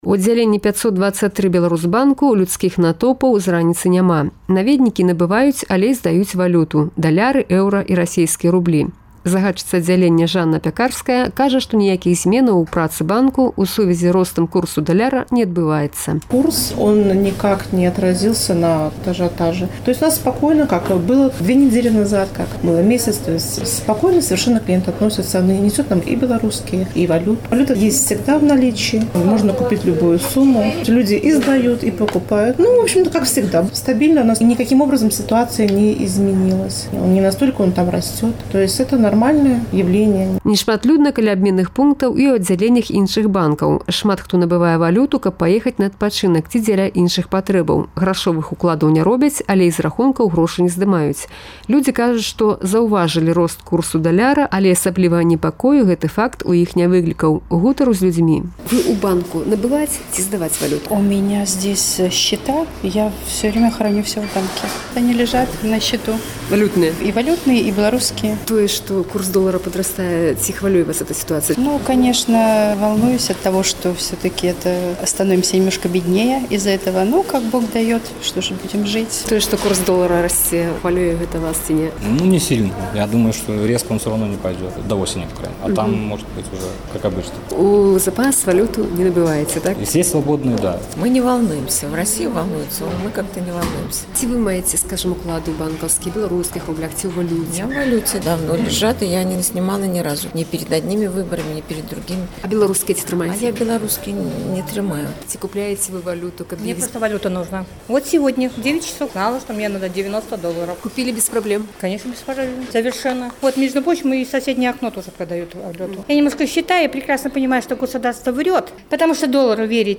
Рэпартаж з абменьніка